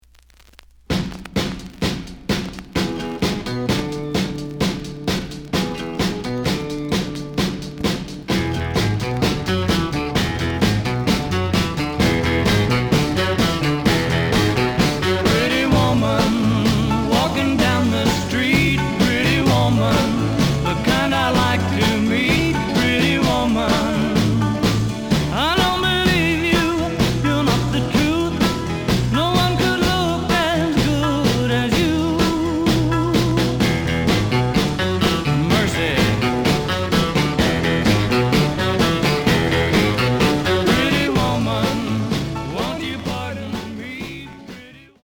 The listen sample is recorded from the actual item.
●Genre: Rock / Pop
Slight edge warp.